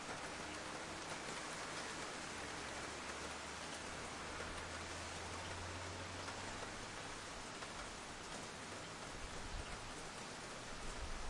描述：Es regnet，下雨了
Tag: 雨滴 再生 淋浴 雨滴 下雨